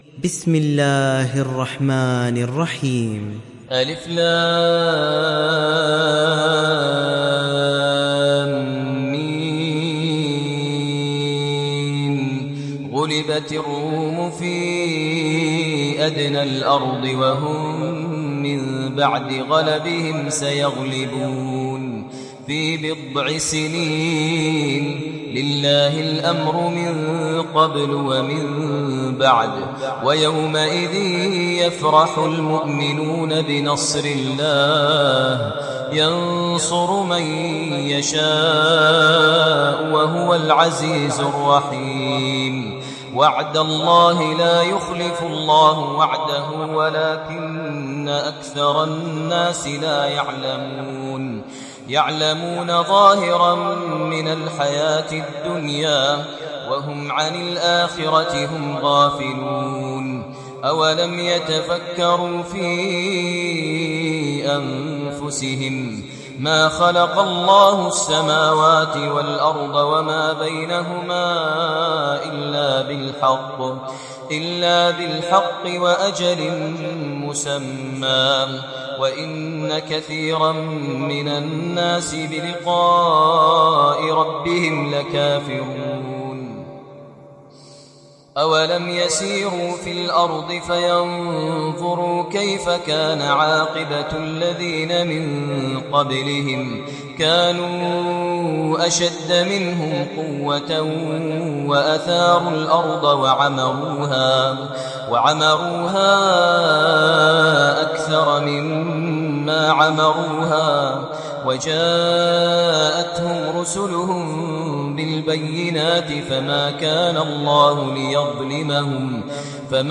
Rum Suresi İndir mp3 Maher Al Muaiqly Riwayat Hafs an Asim, Kurani indirin ve mp3 tam doğrudan bağlantılar dinle